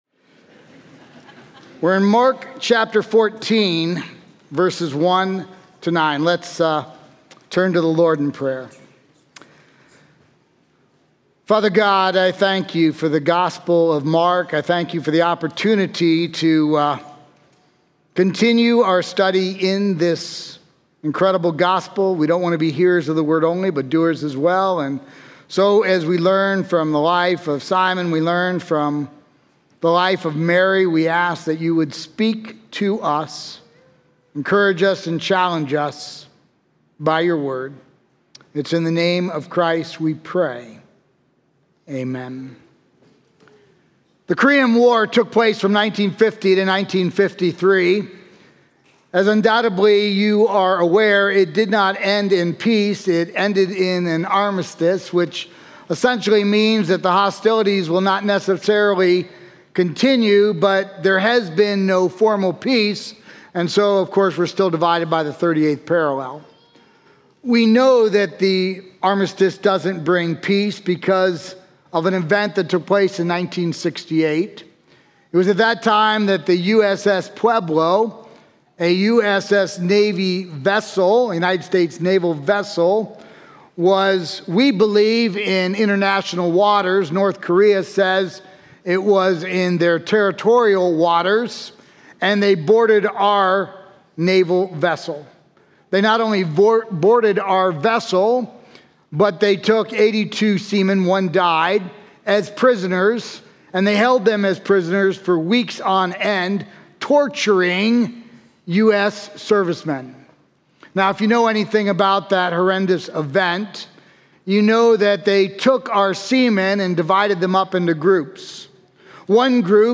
Highland Community Church sermons